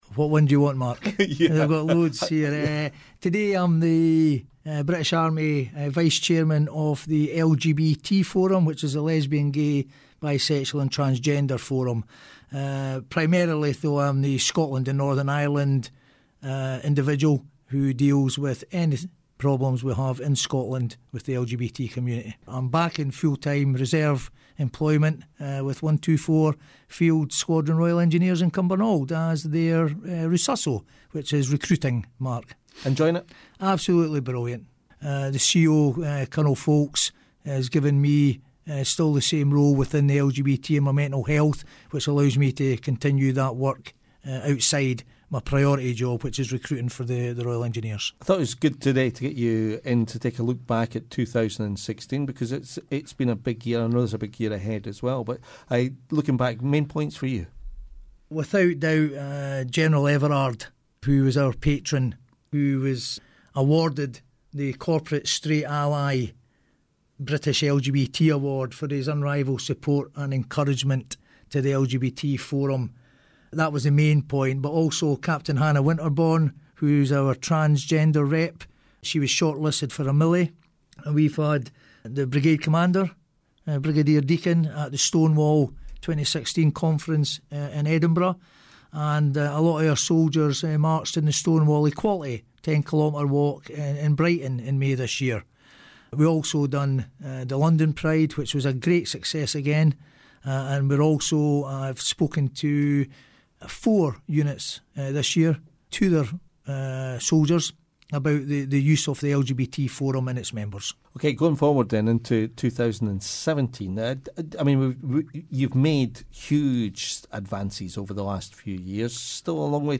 on the BFBS Scotland Breakfast show to look back on the year and ahead to 2017